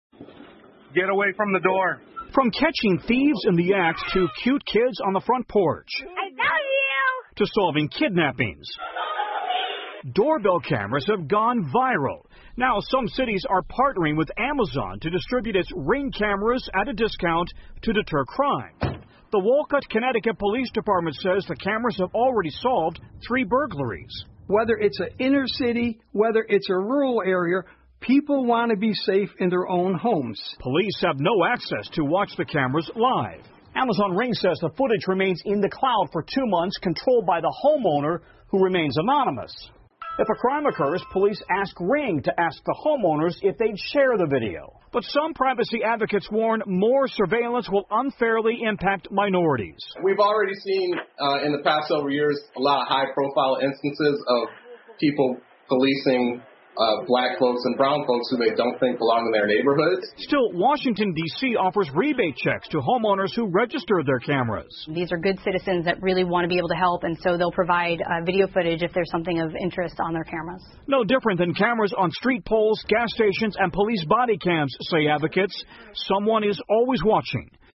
NBC晚间新闻 警方利用门铃相机破案引发担忧 听力文件下载—在线英语听力室